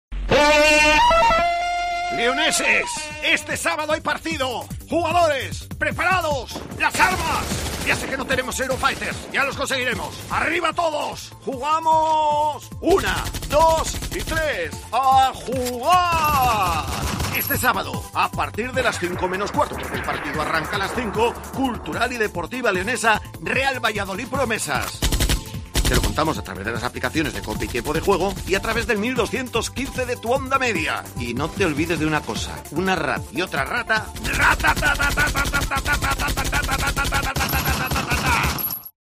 Escucha la cuña promocional del partido Cultural - Valladolid el día 25-09-21 a las 17:00 h en el 1.215 OM